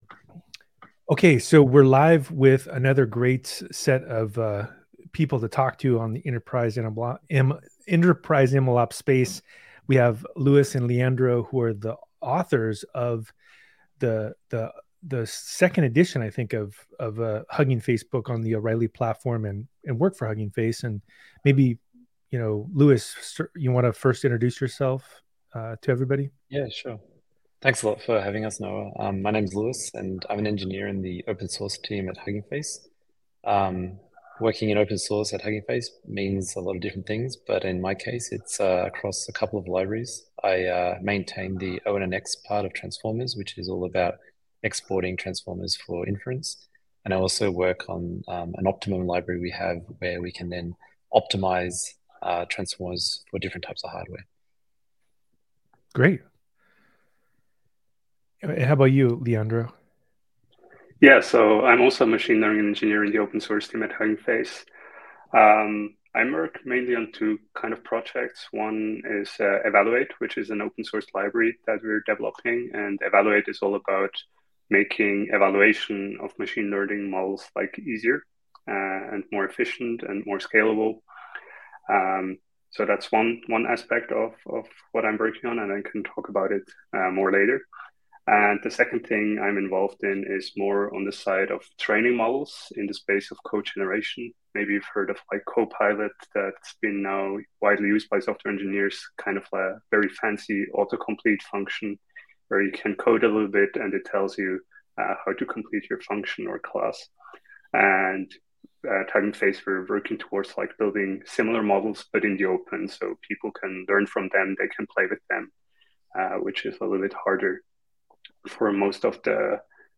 Download - The Little Data Thief Who Could: Chapter Seven-An Eyeball for Data Theft (Narrated with Cloned Voice) | Podbean